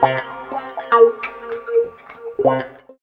90 GTR 2  -R.wav